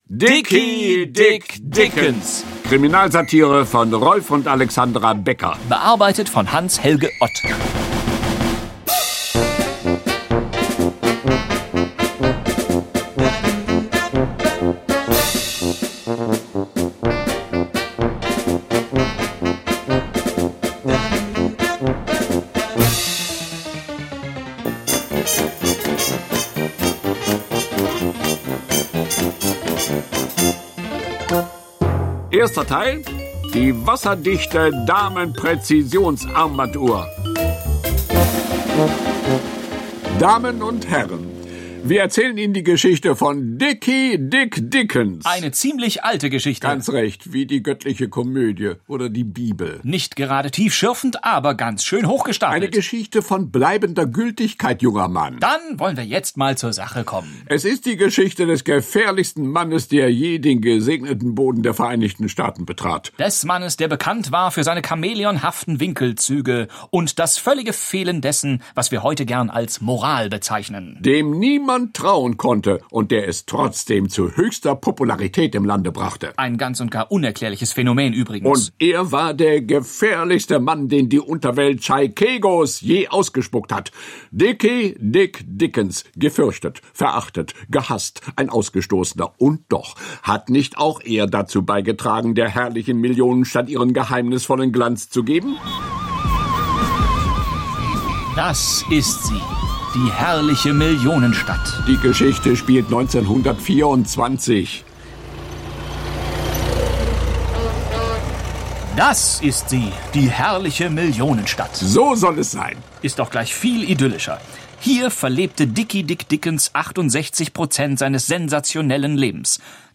Die wasserdichte Damen-Präzisions-Armbanduhr (1) ~ Dickie Dick Dickens – Kriminal-Hörspiel-Serie Podcast